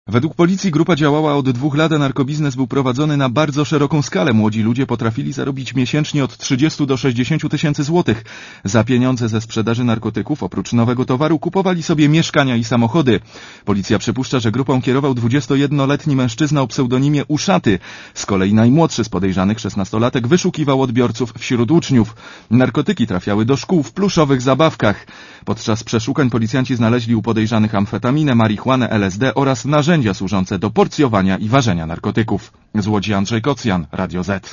Komentarz audio (136Kb)